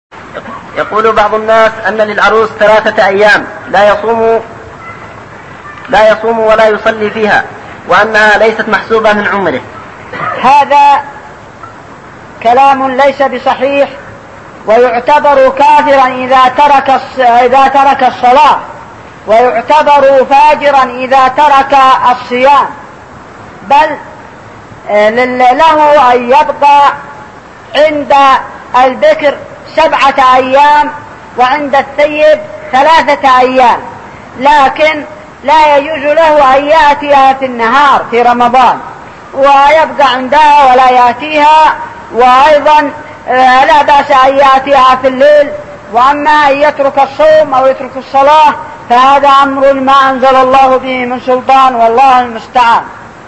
بعض الناس أن للعروس ثلاثة أيام لا يصوم ولا يصلي فيها | فتاوى الشيخ مقبل بن هادي الوادعي رحمه الله